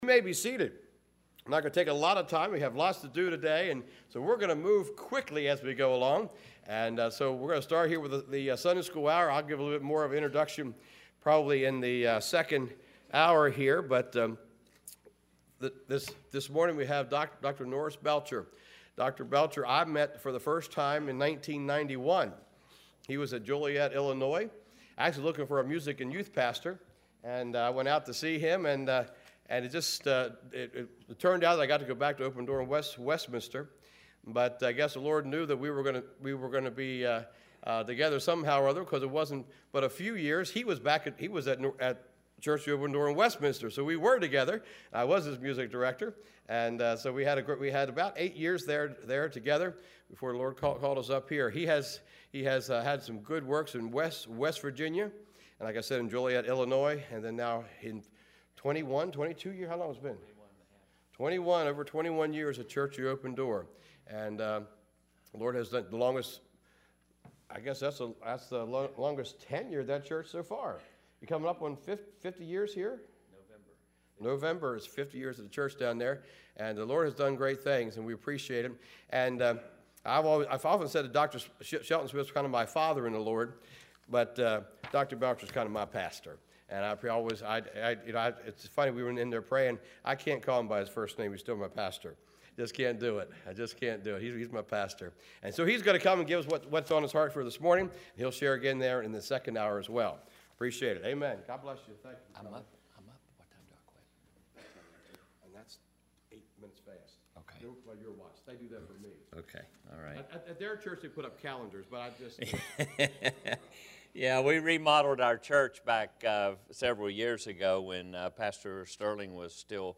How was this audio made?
Matthew 1:1 Service Type: Spring Bible Conference